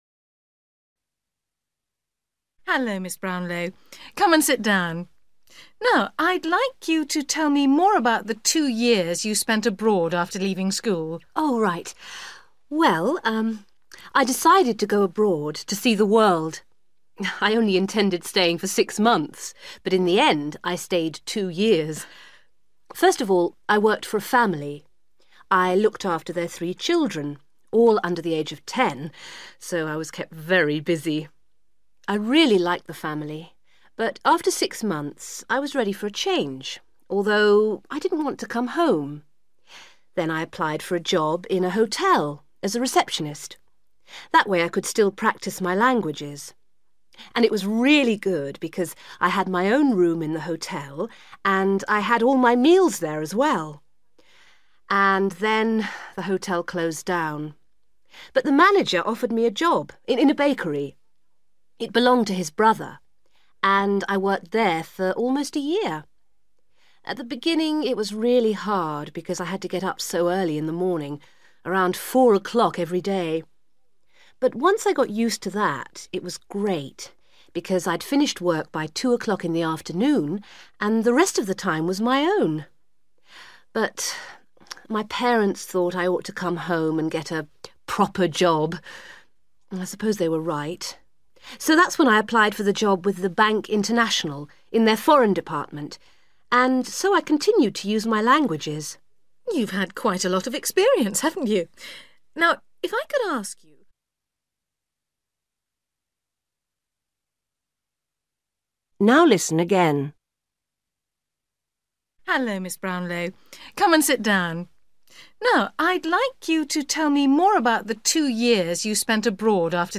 You will hear a young woman who has applied for an office job talking about her jobs abroad.